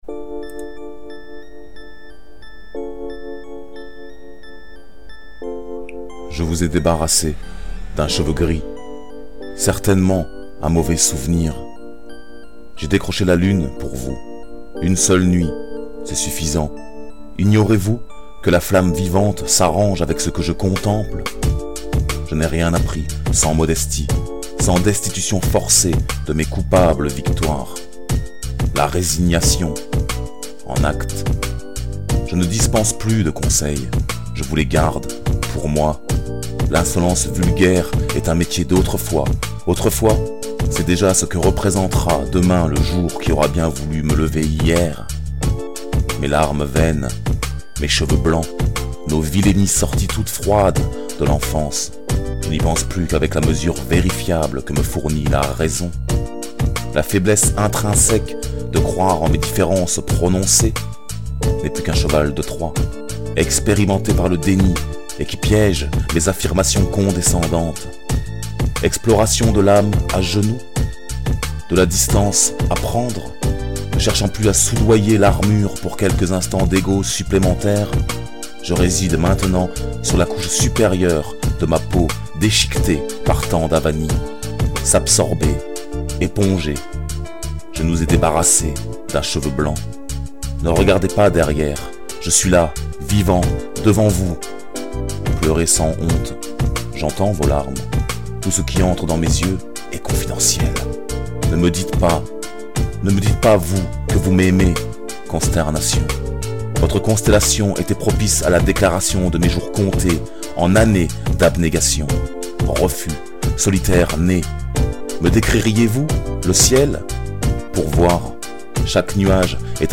Musique de Kinishao « The Lost Story »
Ce contenu a été publié dans Poésiphonies / Versions audio.